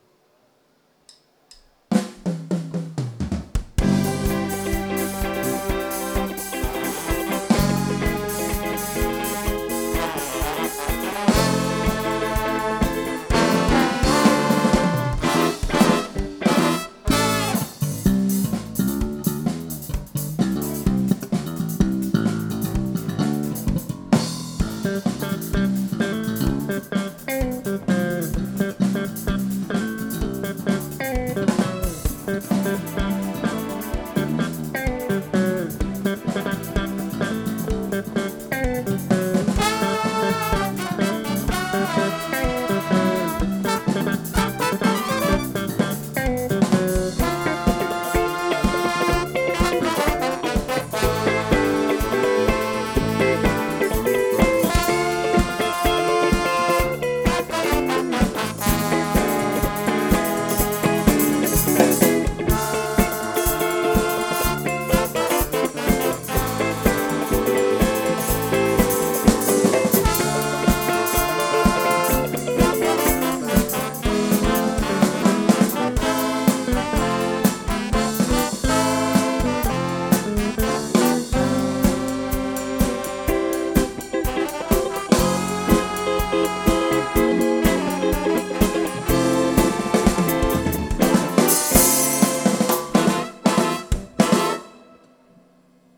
· Genre (Stil): Soul